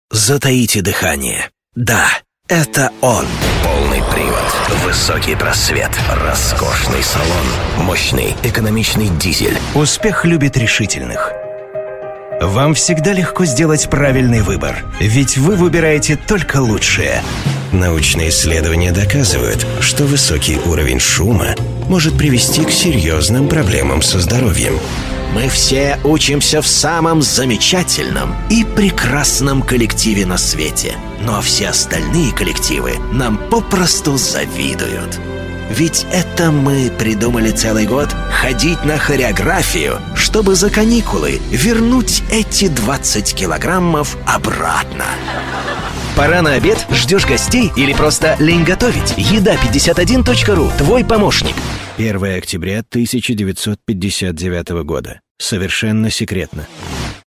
Диктор Купряшин Владислав
Этот бас-баритон пользуется заслуженной дикторской славой по всей России.